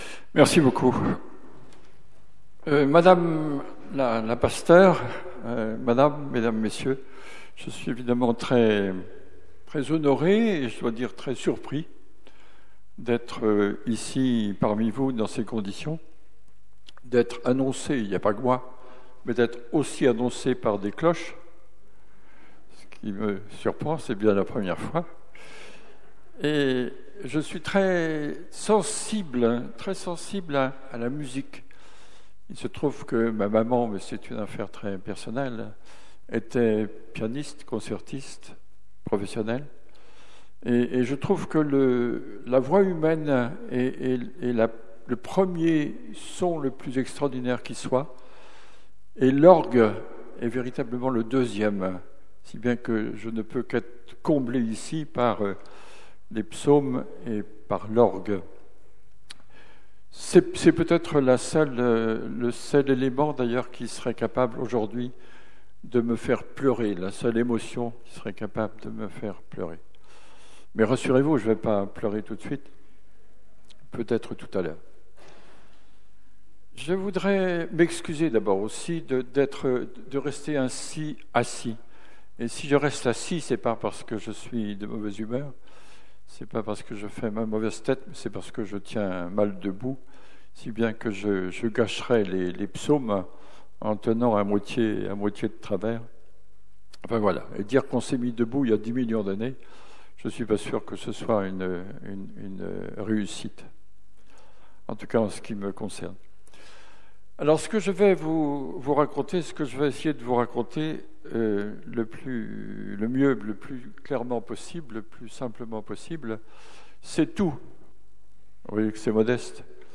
Culte-conférence du 30 janvier 2022
par Yves Coppens Paléontologue, Professeur au Collège de France
Version courte (conférence sans culte)